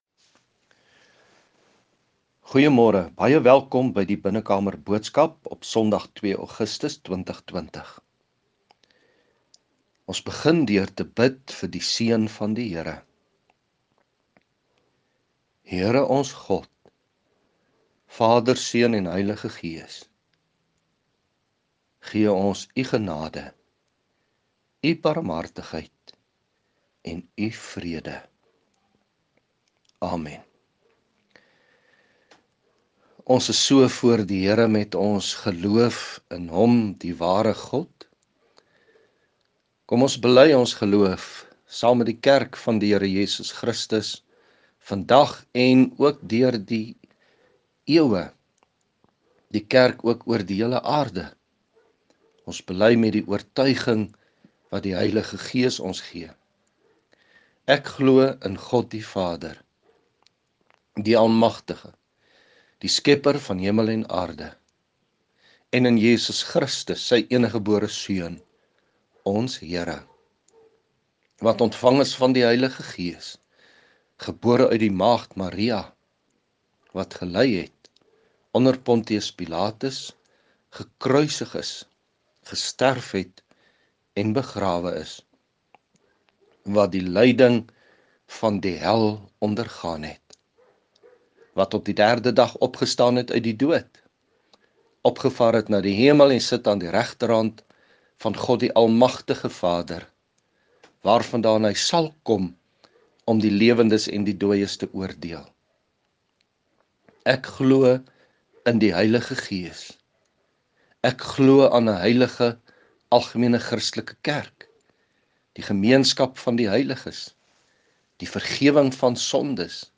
Die verandering is die tema vir vanoggend se preek: Tema: Vrede met God laat my geestelik groei wanneer ek swaarkry.